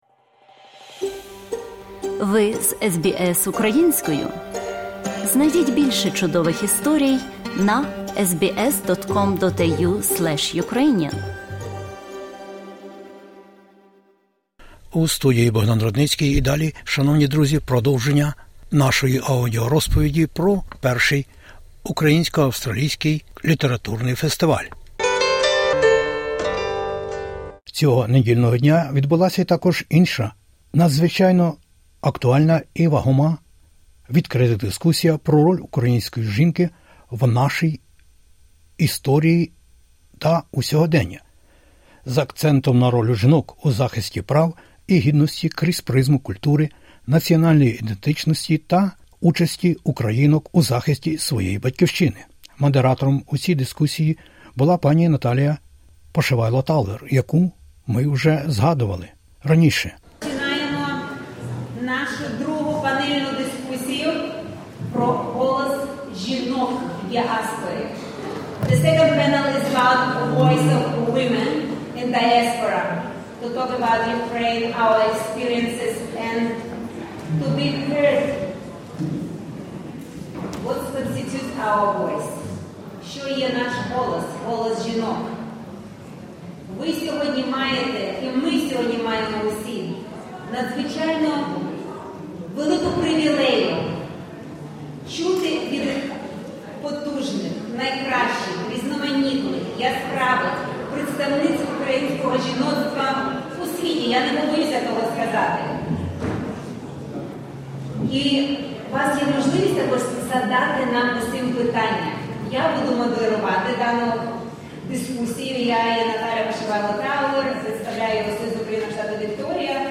First Ukrainian-Australian Book Fest 2025.